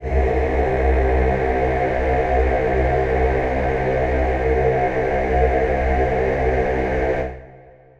Choir Piano
C2.wav